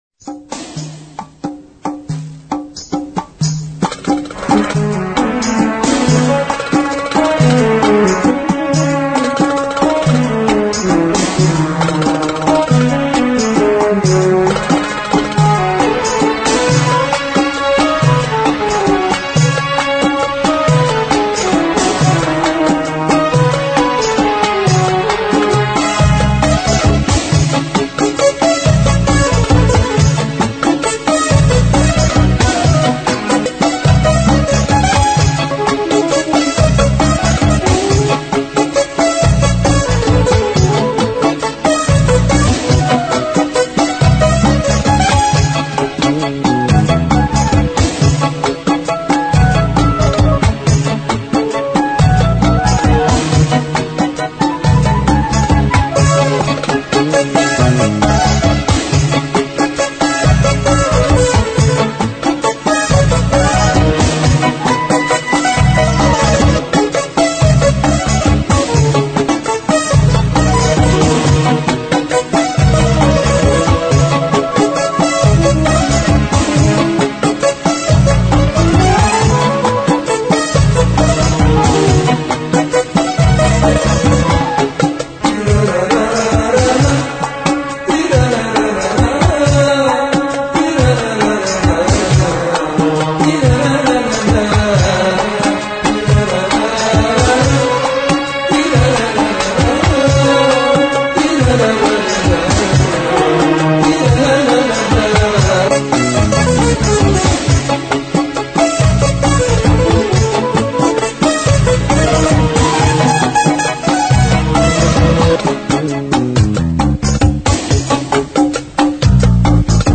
musique instrumentale